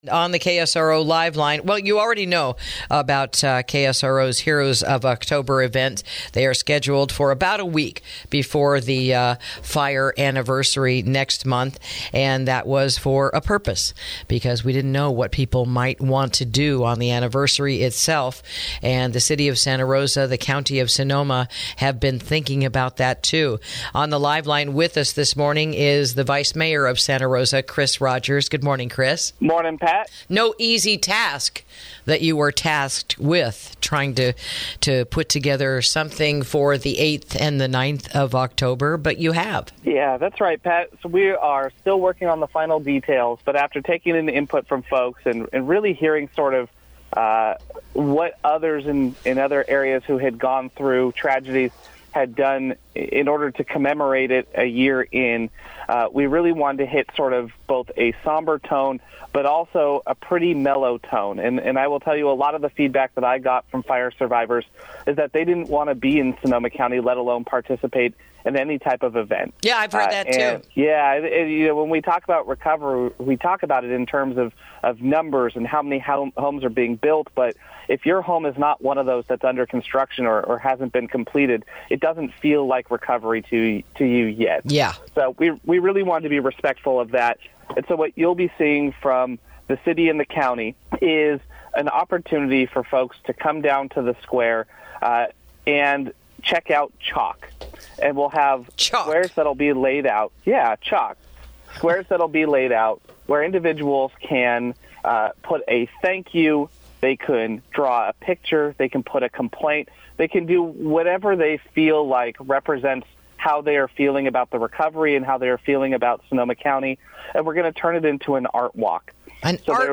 INTERVIEW: The Fire Memorial Art Walk to Commemorate the One Year Anniversary of the North Bay Wildfires